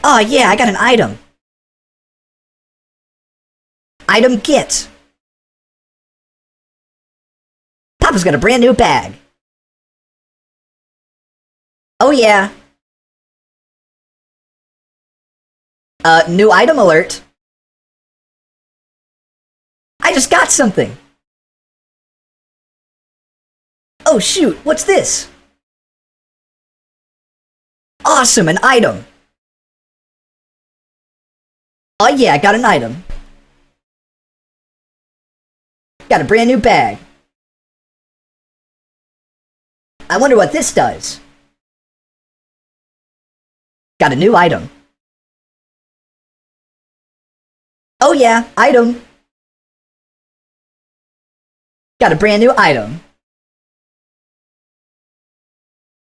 Grab_item.ogg